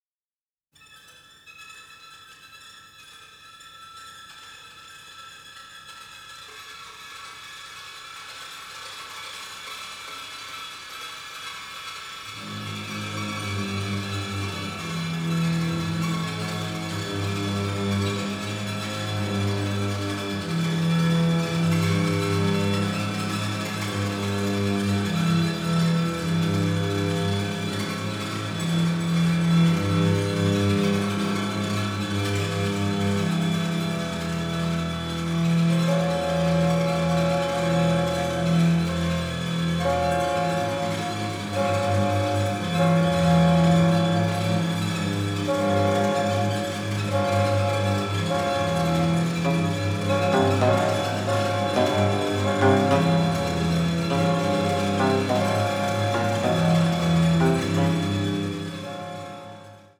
in full stereo for the first time.
the melancholic, the macabre and the parodic